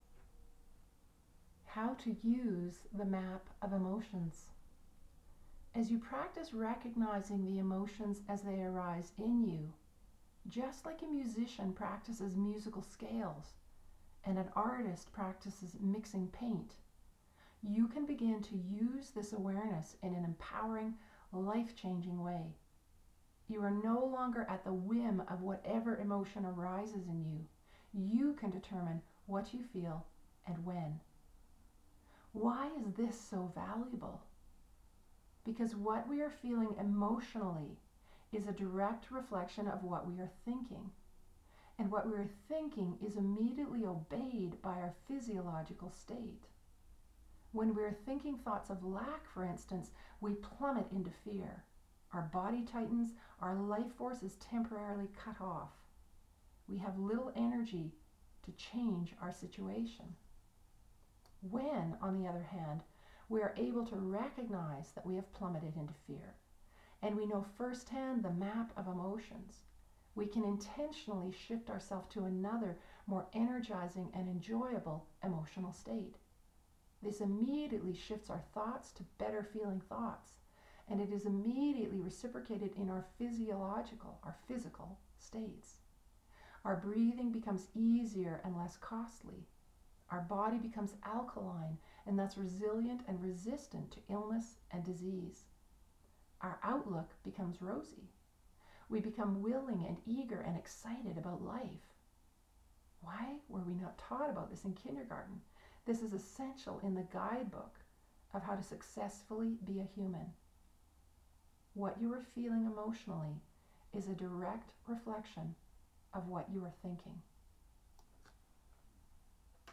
How to Use the ‘Map of Emotions’ recorded using the omni-dimensional ‘Star Microphone’ by Wehnersound.